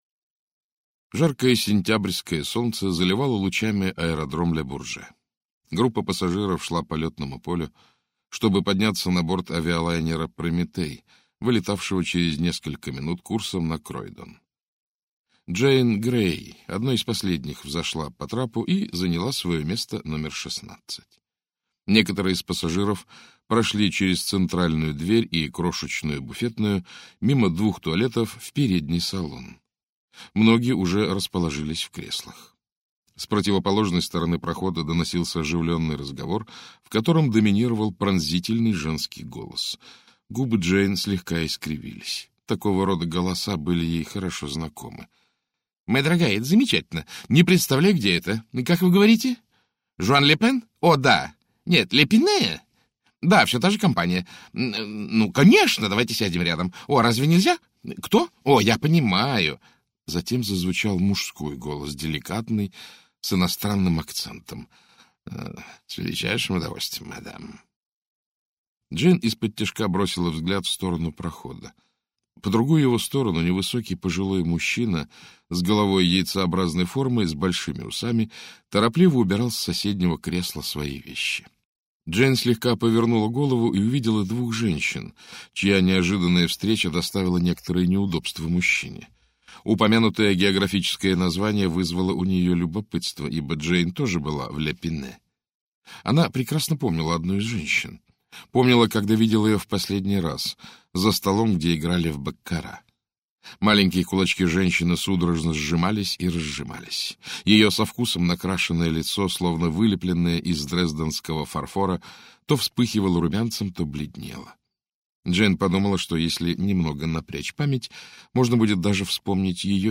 Аудиокнига Смерть в облаках - купить, скачать и слушать онлайн | КнигоПоиск